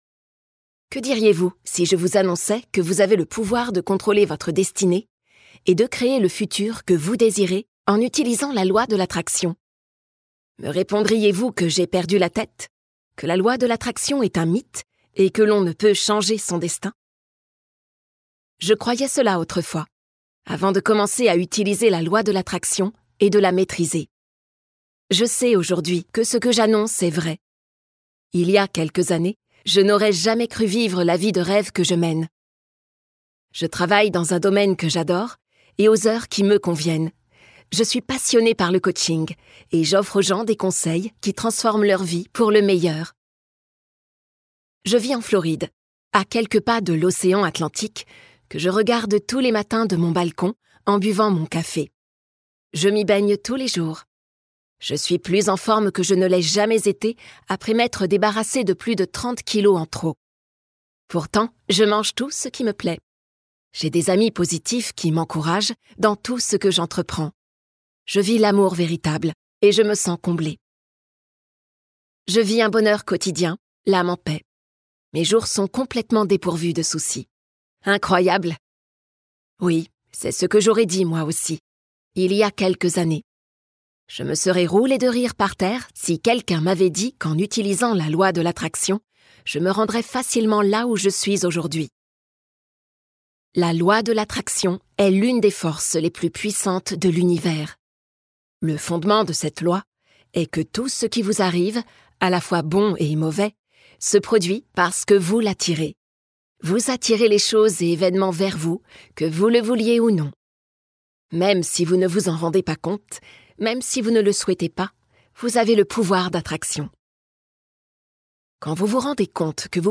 LE BONHEUR POUR TOUS : Ce titre de développement personnel vous présente les principes de la loi de l'attraction. Si ce concept vous est totalement étranger ou vous paraît flou, ce livre audio pour les Nuls le décrypte pour vous !